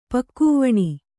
♪ pakkūvaṇi